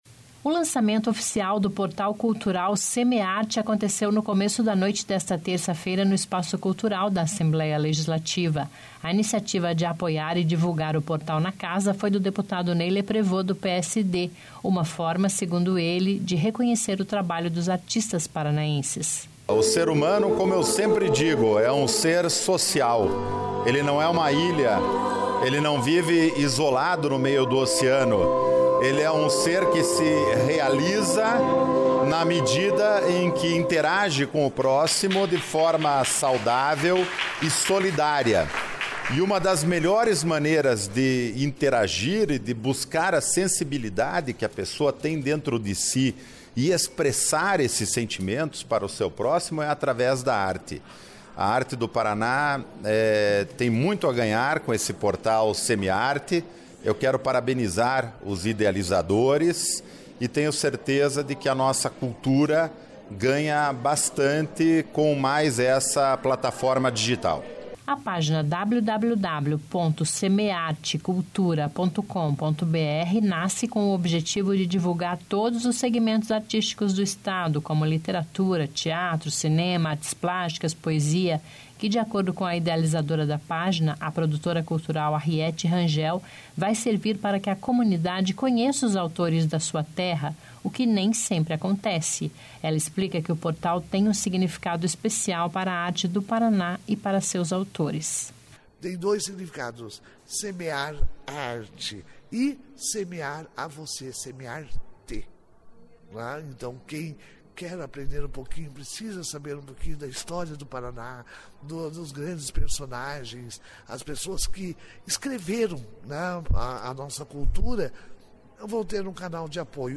Notícia